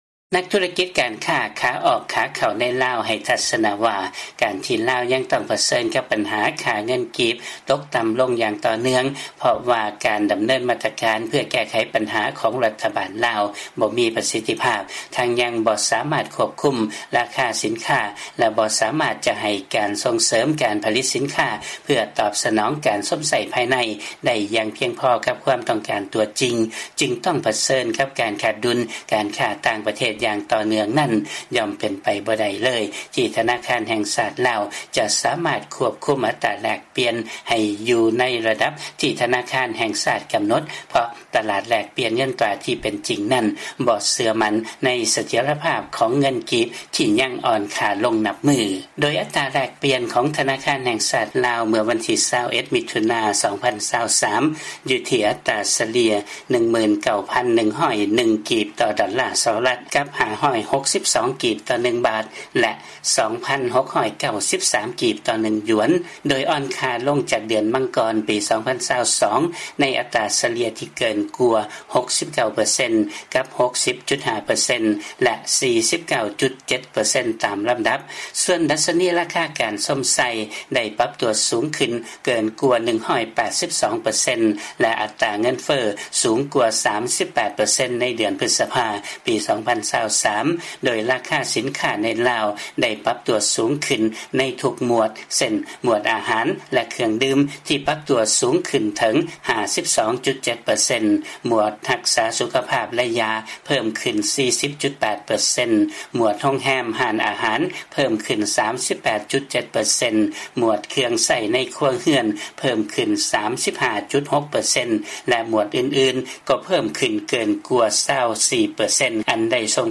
ມີລາຍງານເລື້ອງນີ້ຈາກບາງກອກ